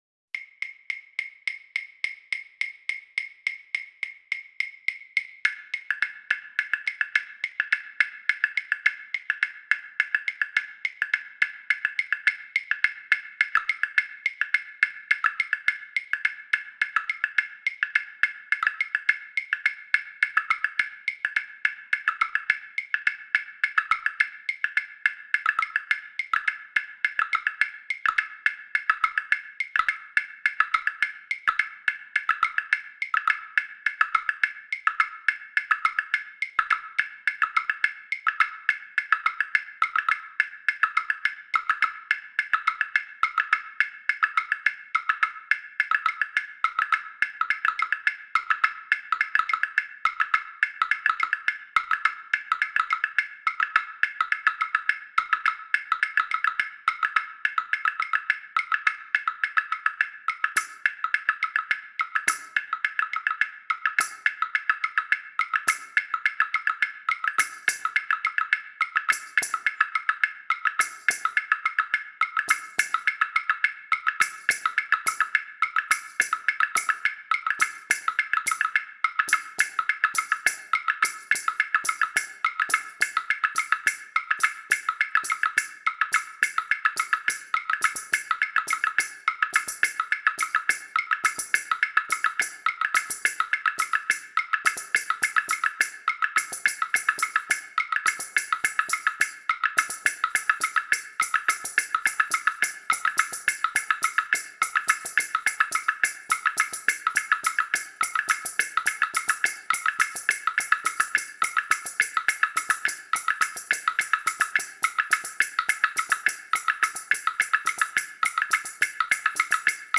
Chilenita Nº1 para percusión